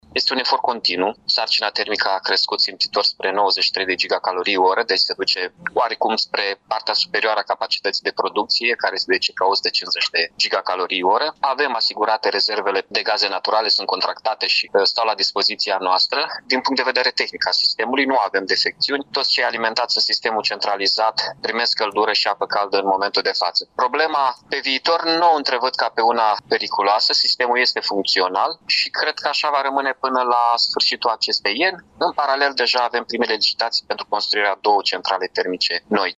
Edilul a declarat că nu au fost probleme în alimentarea cu agent termic pentru cele 22 de mii de apartamente, 10 spitale, 135 de instituții și 170 de agenți economici din Iași.